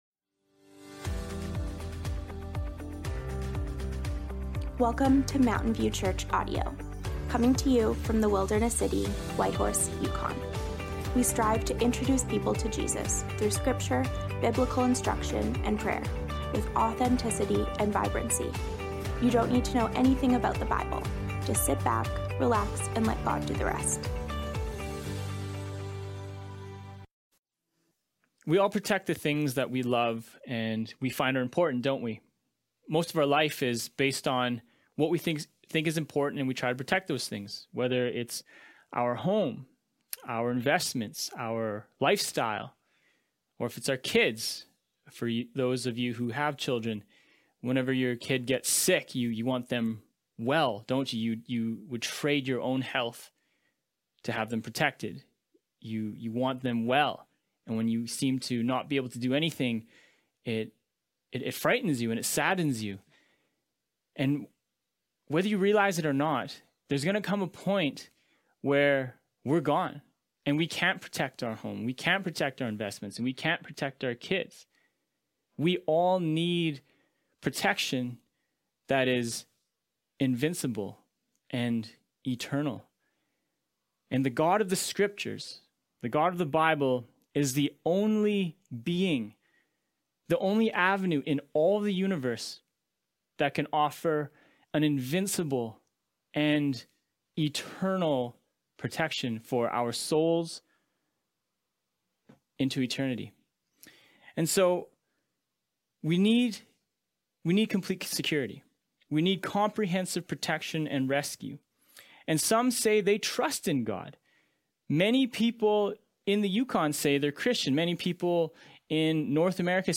The Invincible Love and Protection of God (Psalms, Ep. 8 - Sermon Audio)